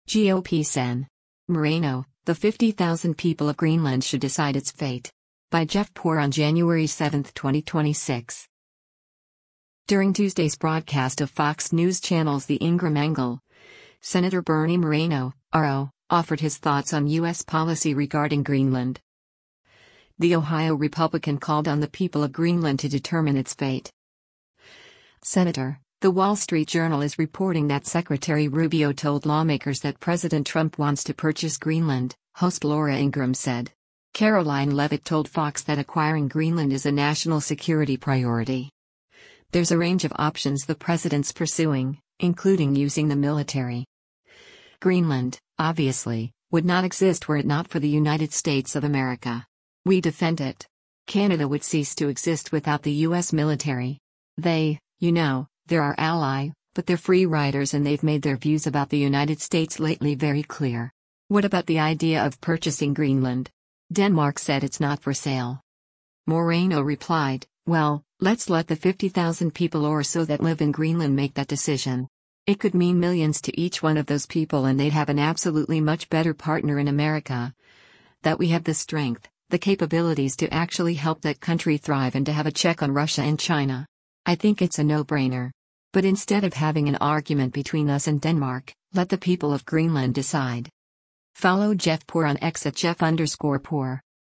During Tuesday’s broadcast of Fox News Channel’s “The Ingraham Angle,” Sen. Bernie Moreno (R-OH) offered his thoughts on U.S. policy regarding Greenland.